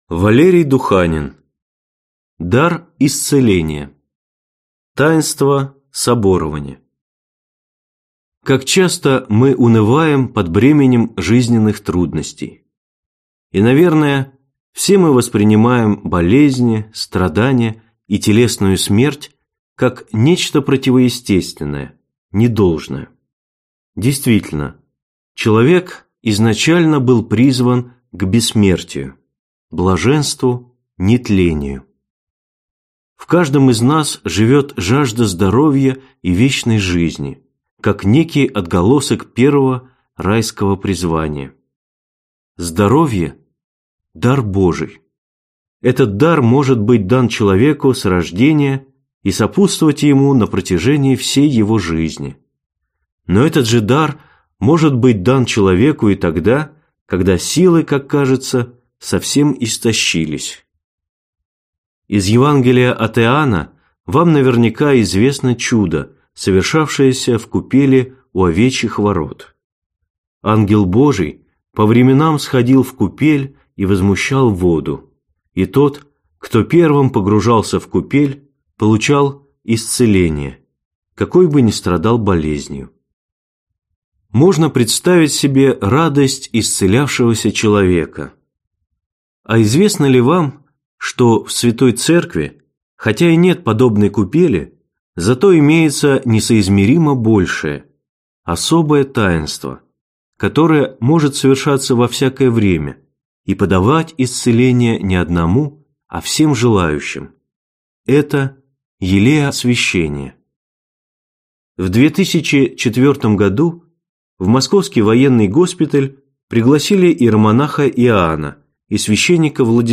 Аудиокнига Дар исцеления: Таинство Соборования | Библиотека аудиокниг